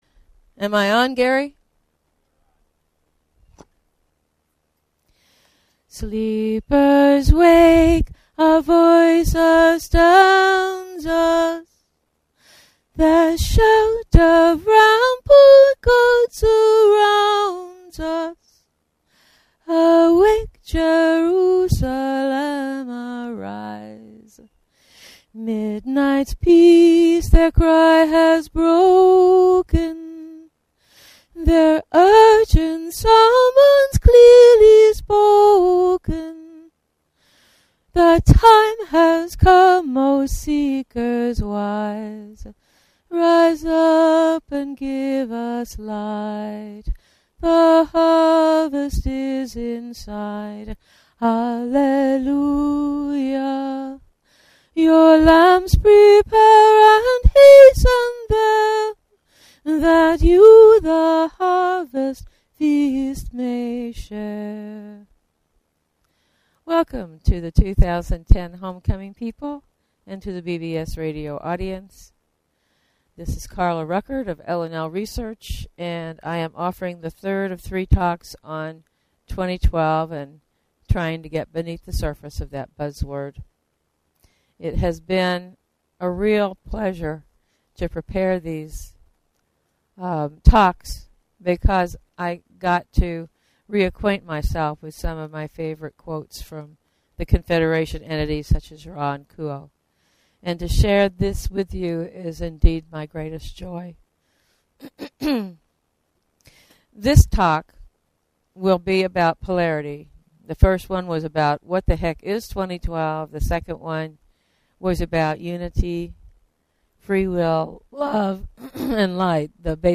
Talk Show Episode, Audio Podcast, LLResearch_Quo_Communications and Courtesy of BBS Radio on , show guests , about , categorized as